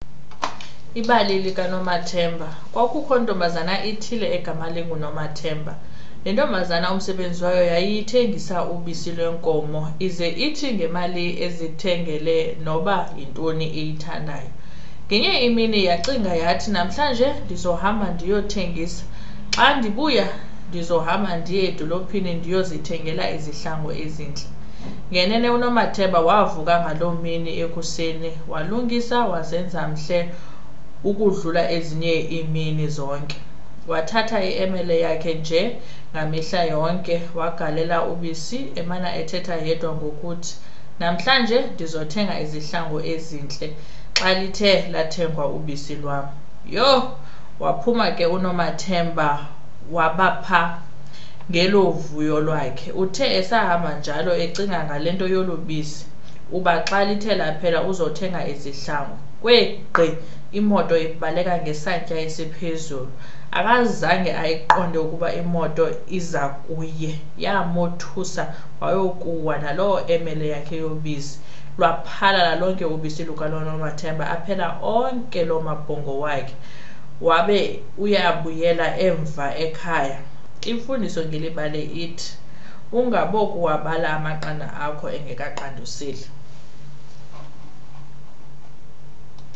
코사어 음성